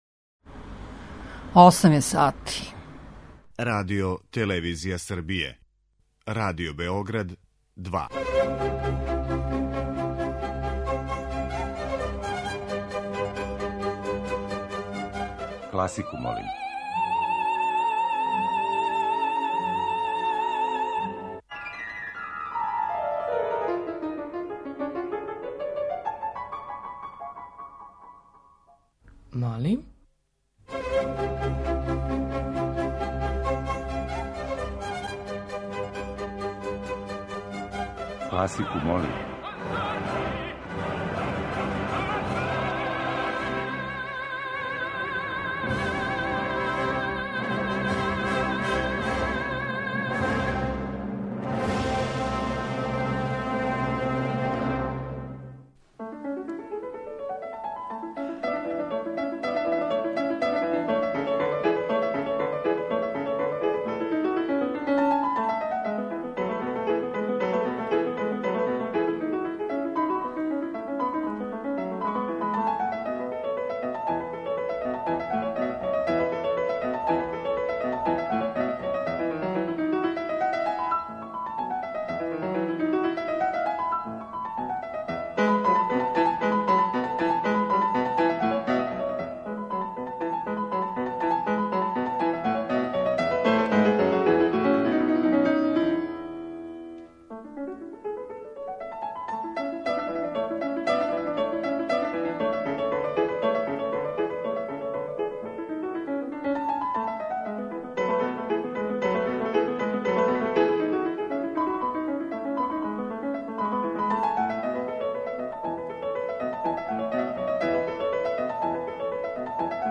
Избор за топ-листу класичне музике Радио Београда 2